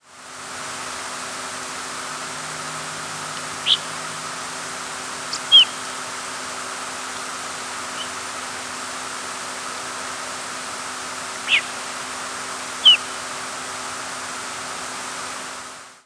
presumed Rose-breasted Grosbeak nocturnal flight calls
Nocturnal flight call sequences: